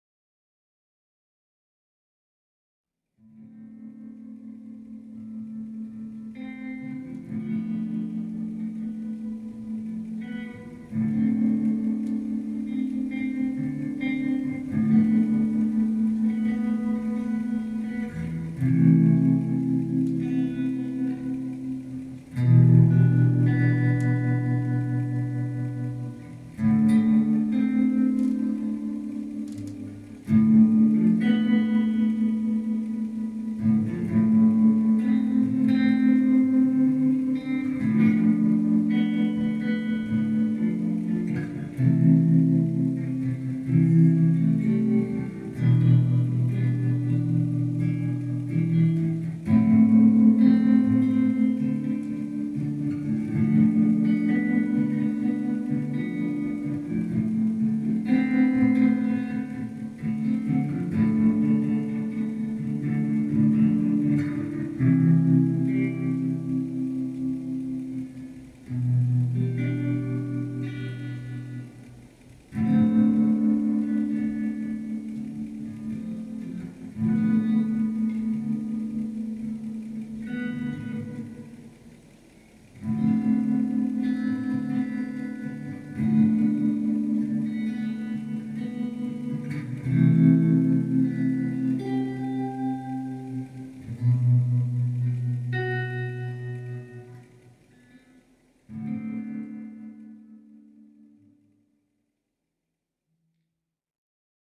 Soundtrack improvised and recorded